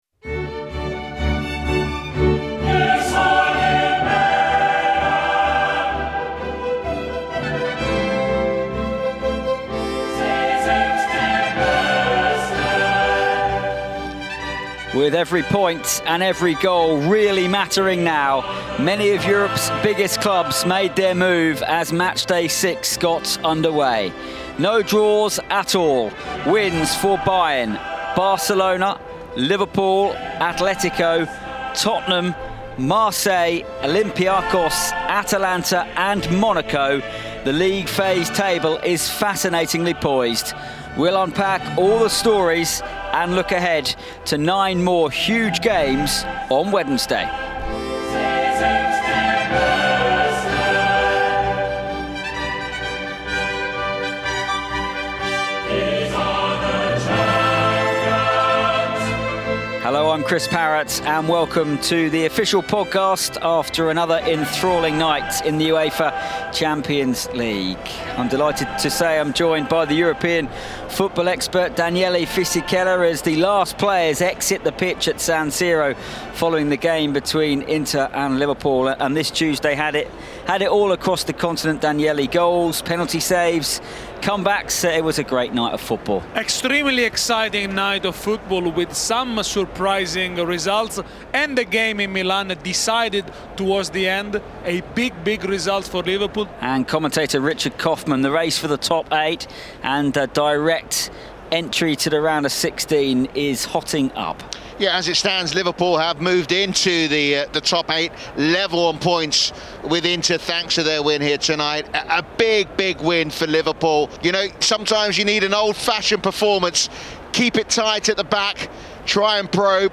In our featured commentary in Milan, Liverpool struck late to sink Inter, thanks to a Dominik Szoboszlai penalty.